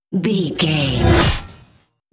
Whoosh electronic fast
Sound Effects
Atonal
Fast
futuristic
intense